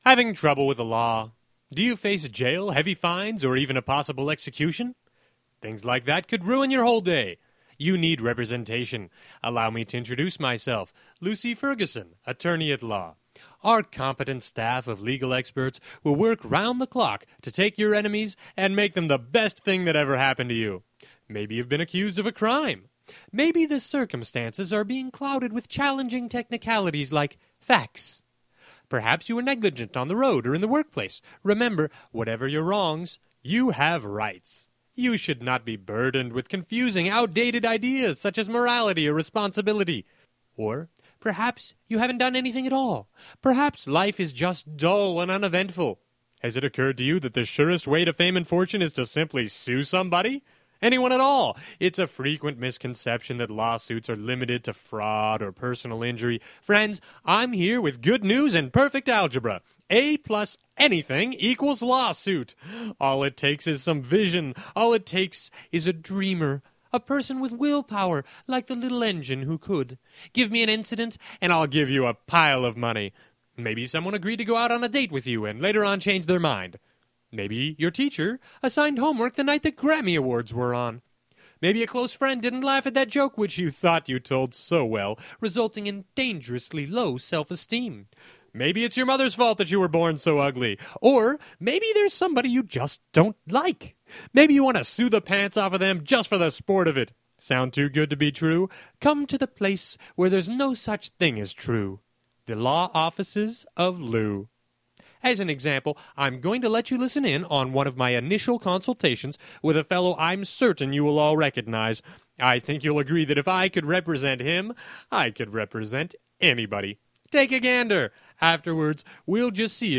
A brief sketch, perfect for churches want to fit short message dramas into their morning or evening services